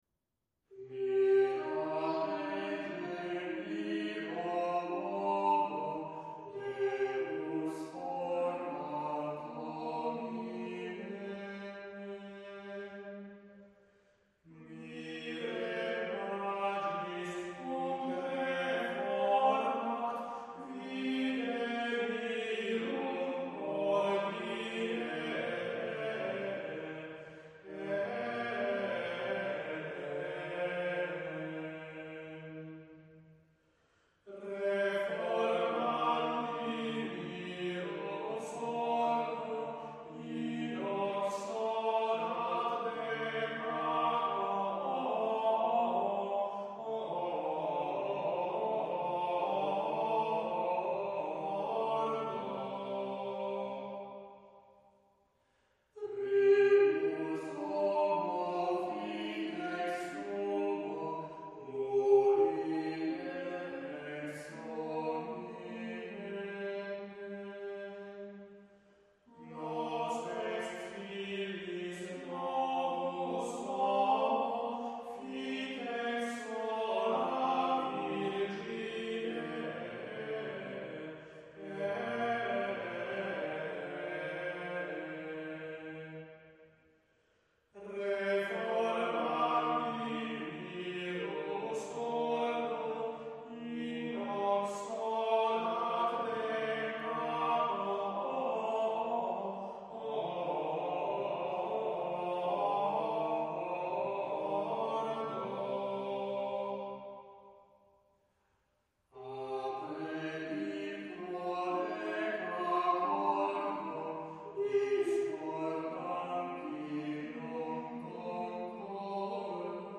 [stile sillabico]